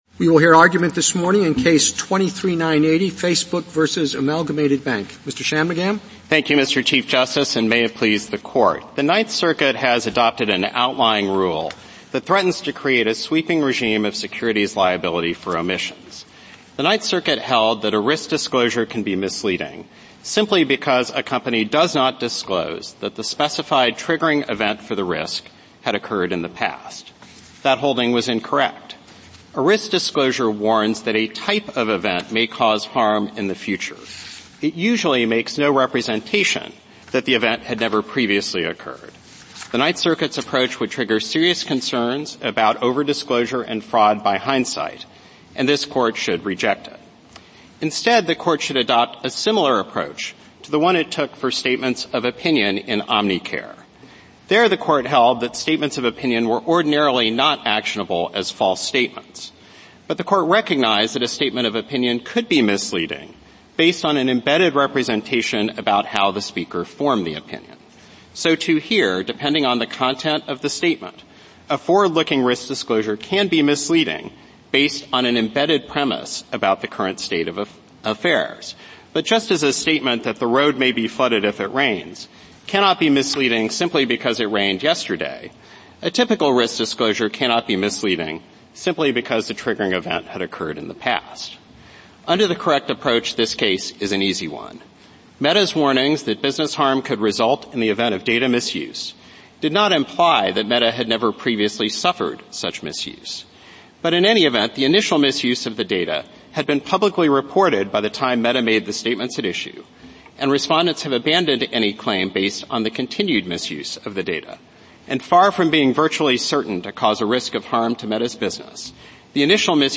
U.S. Supreme Court Oral Arguments